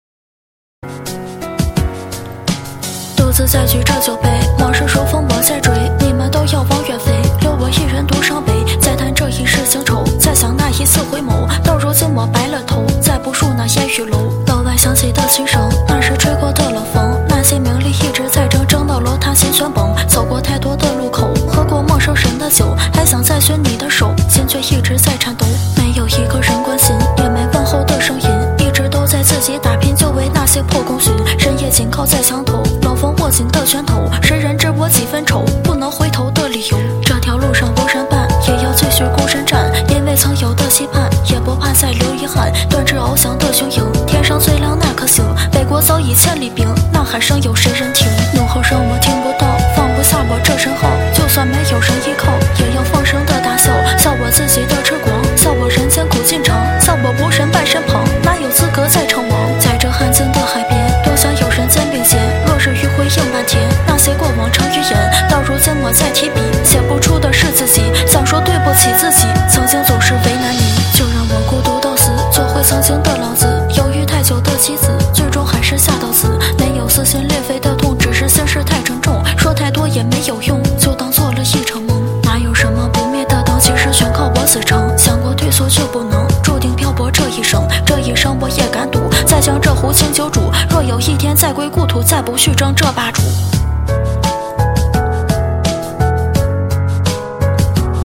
• 舞曲类别： 现场喊麦